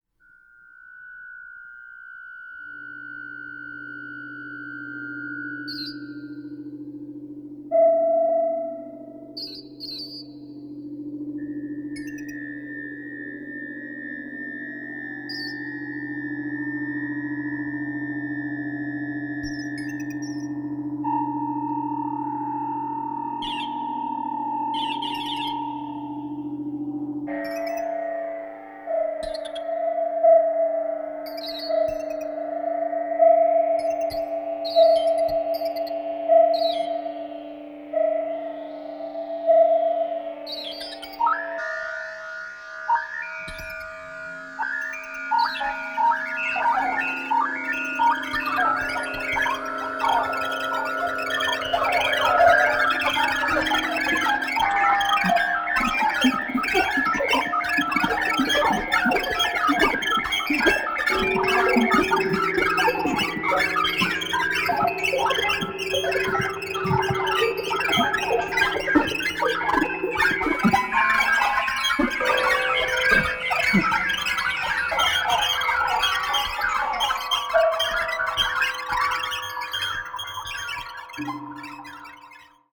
The atonality of the score is profound